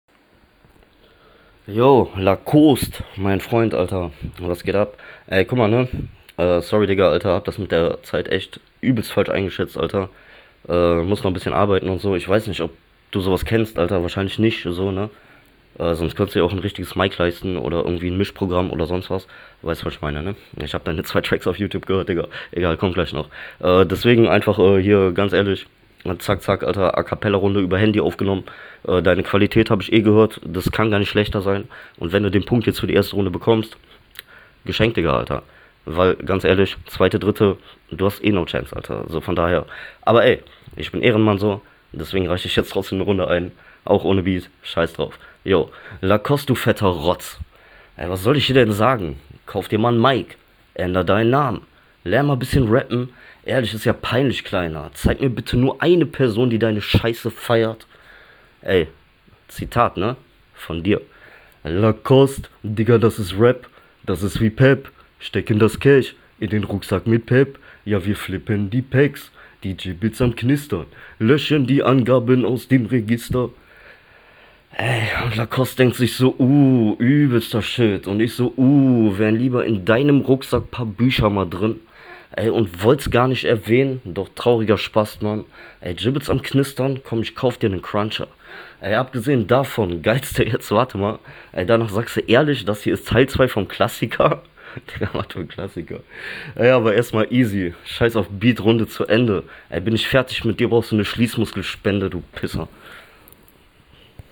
Driveby Runde